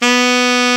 Index of /90_sSampleCDs/Roland L-CD702/VOL-2/SAX_Alto Short/SAX_A.ff 414 Sh
SAX A.FF B04.wav